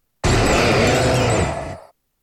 Cri de Séracrawl dans Pokémon X et Y.